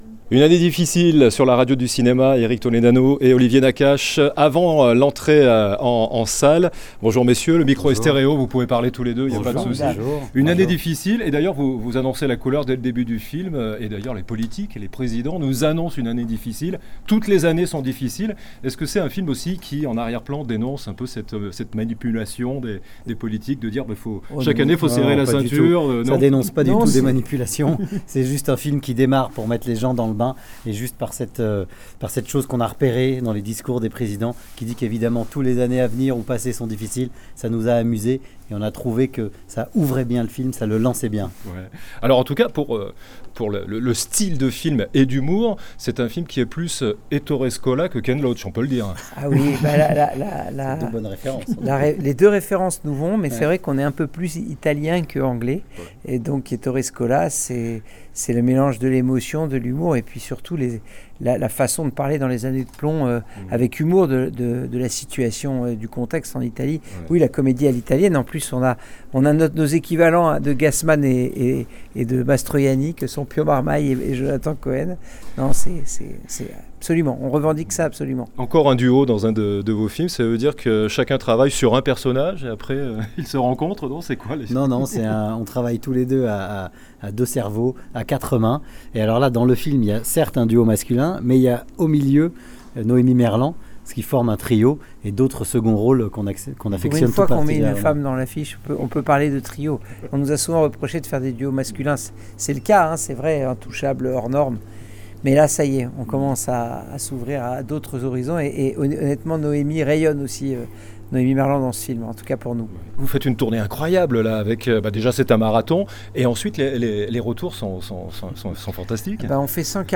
Olivier Nakache et Éric Toledano présentent la comédie "Une année difficile" sur LA RADIO DU CINEMA
Il s'agit bien d'une comédie, pas d'un film politique. Olivier Nakache et Éric Toledano font cette mise au point lors de leur marathon d'avant-premières pour "une année difficile" 4 mois avant sa sortie en salles.